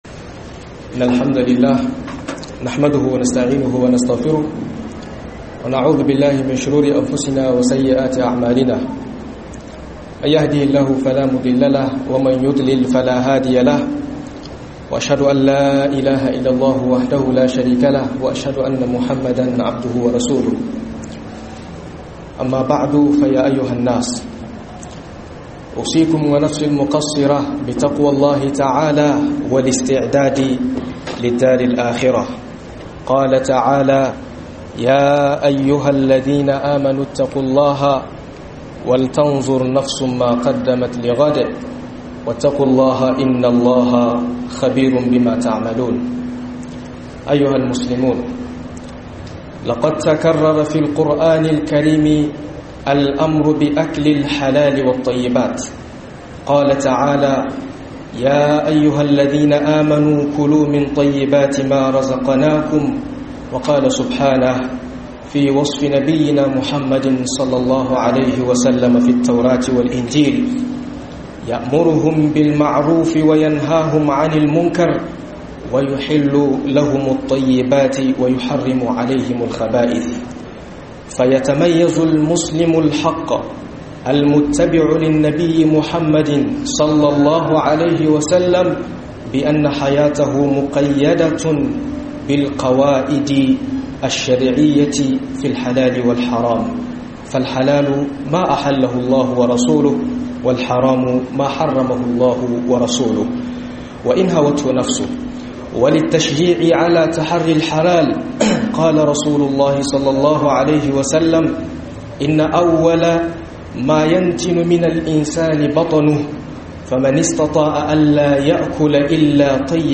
HUDUBOBI